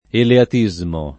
eleatismo
[ eleat &@ mo ]